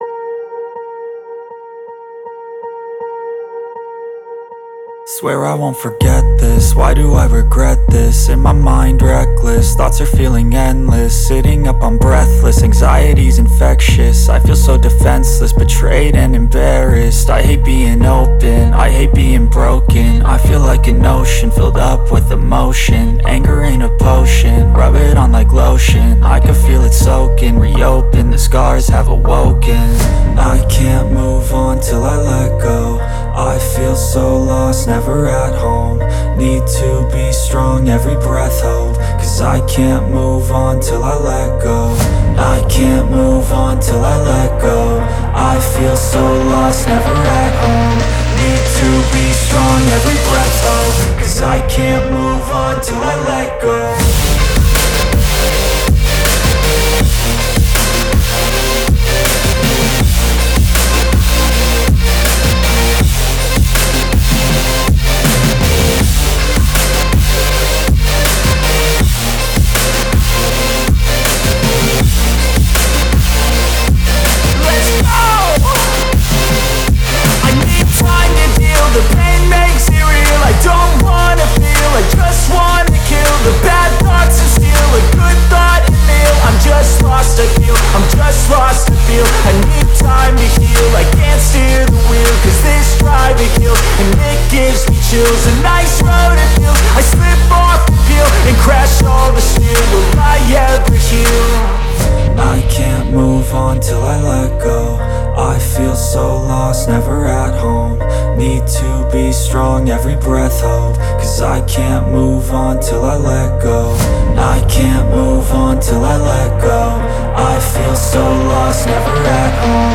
Dance e Eletrônica